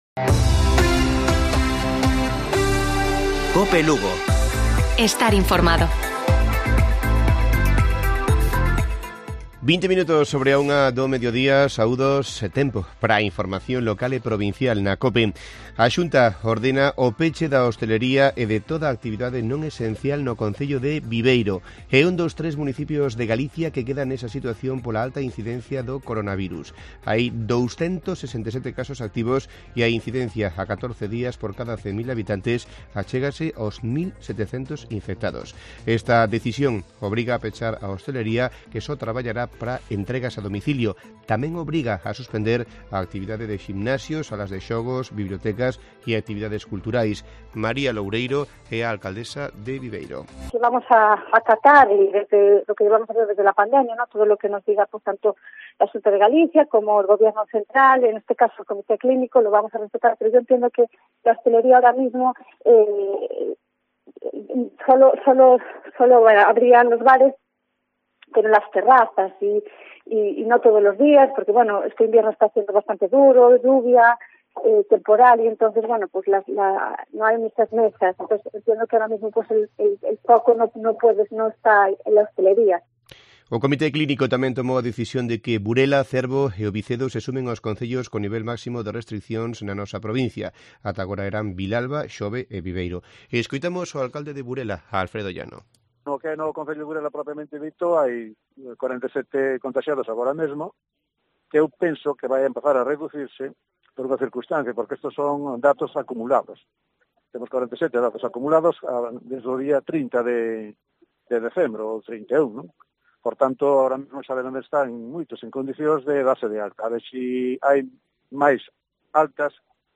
Informativo Provincial de Cope Lugo. 19 de enero. 13:20 horas